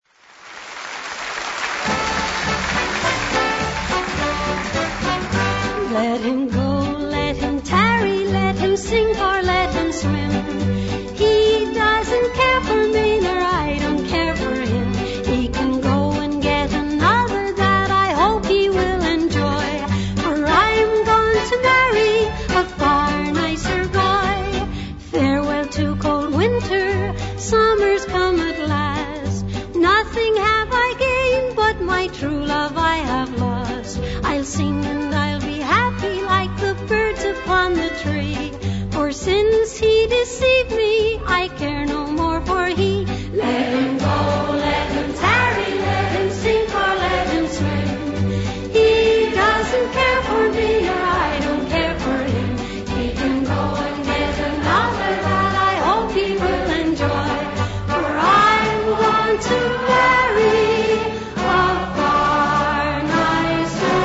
live version